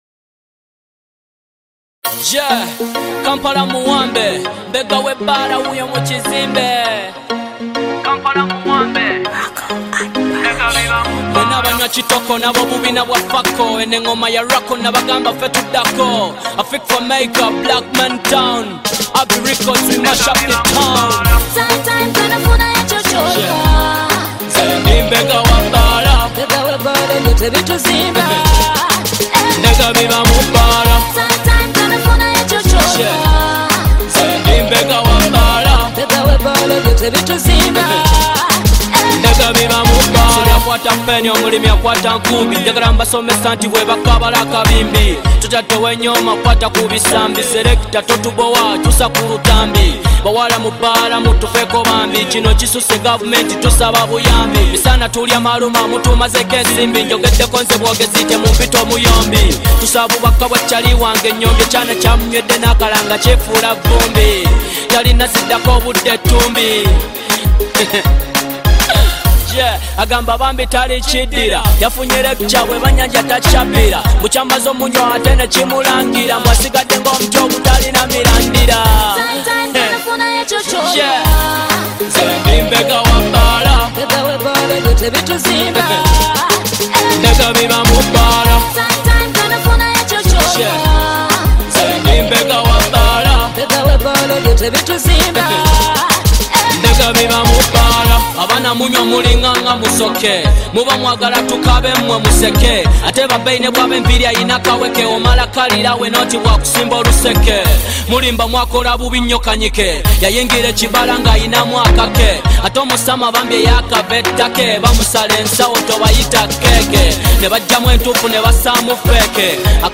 Genre: Rap Music